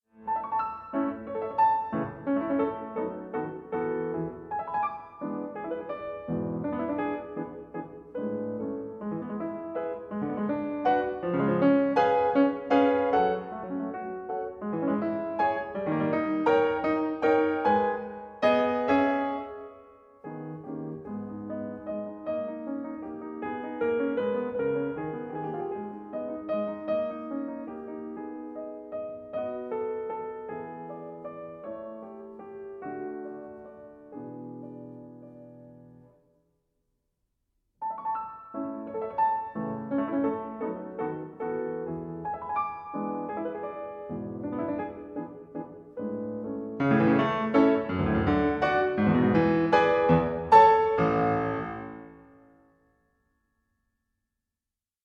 Much of the scherzo's sprightly feel comes from Beethoven's witty positioning of rests, which never occur in both hands at the same time.
But as a whole, the movement still brims with life.
PIANO MUSIC